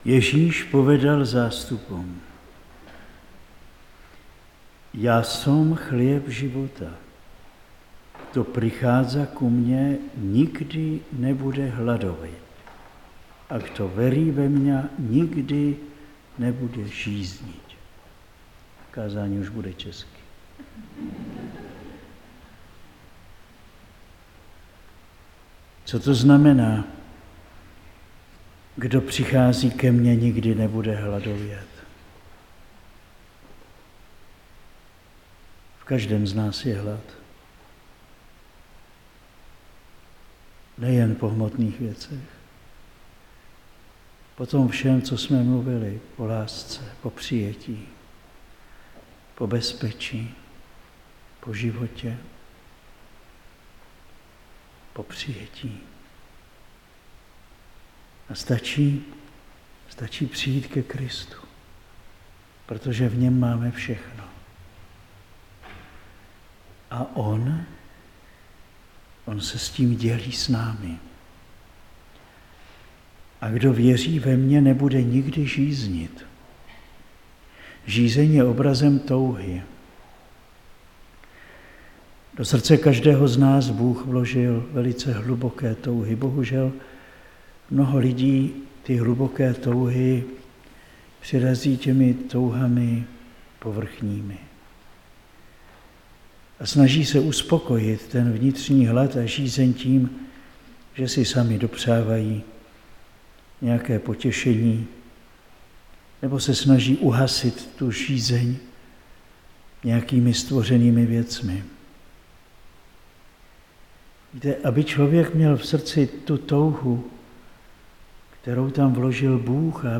Záznam kázání ze mše svaté na duchovní obnově v Bratislavě (7. 5. 2025) si můžete stáhnout na tomto odkazu.